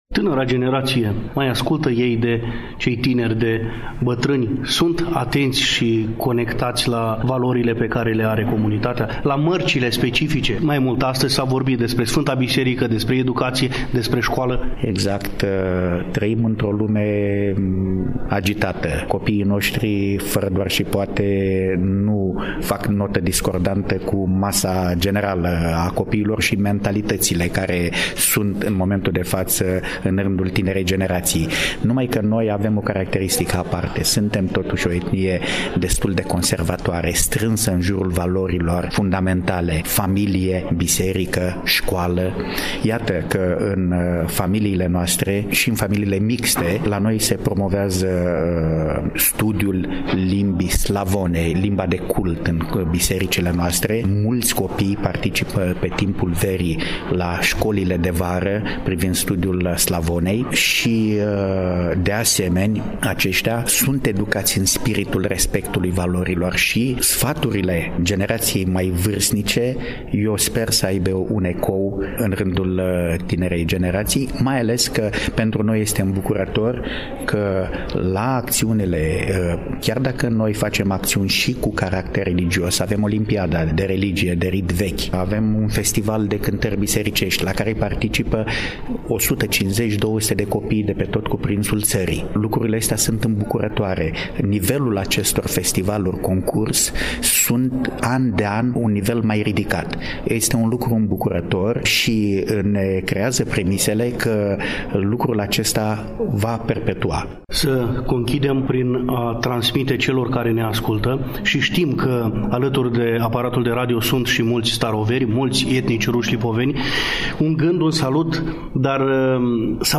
Imediat după lansarea romanului Ambasadorul invizibil, de Nichita Danilov, volum aflat la ediția a II-a, apărut la Editura POLIROM din Iași, dar și după prezentarea broșurii – Centenarul Marii Uniri. Etnicii Ruși Lipoveni – editată de către membrii redacției „Zorile” sub egida Centrului de Studii și Cercetări privind Rușii Lipoveni din România, am invitat la dialog pe domnul Andrian Ampleev, deputat în Parlamentul României din partea Comunității Rușilor Lipoveni din România.